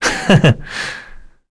Esker-Vox-Laugh_kr.wav